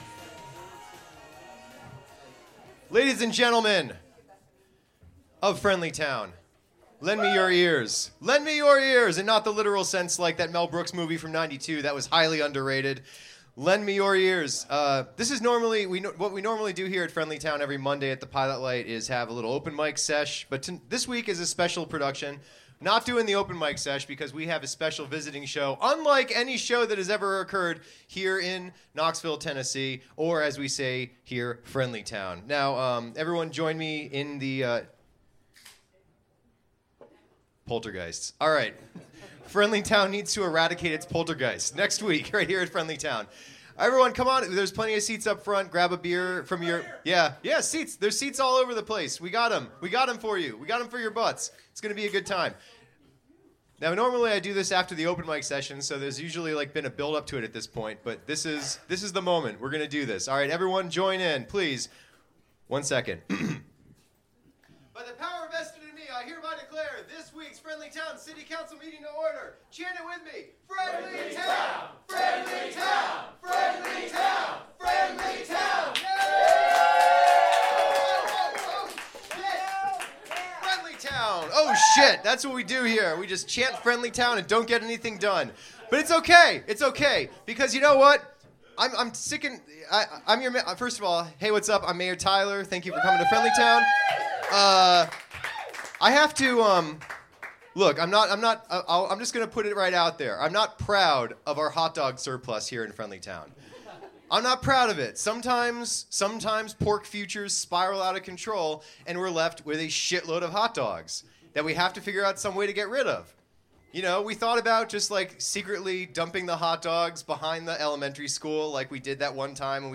This is the broadcast of what happened on that night.